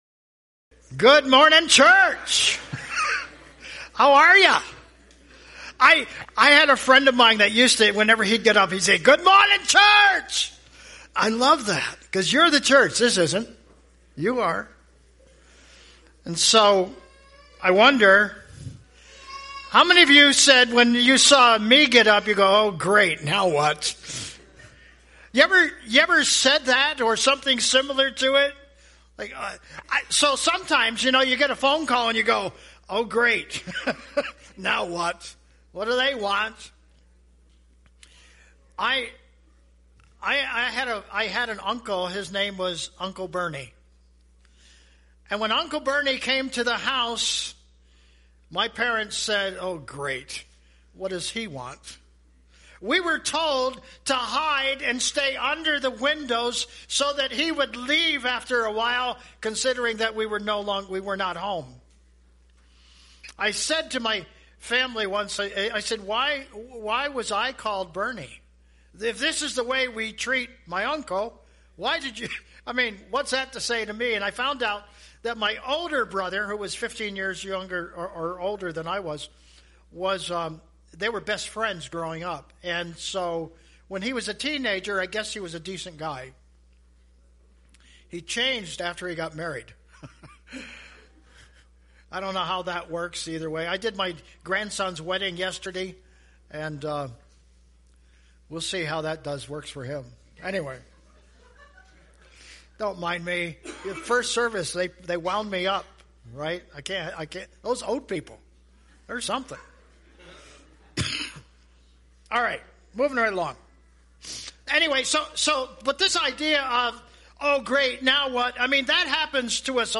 – Curwensville Alliance Church Podcasts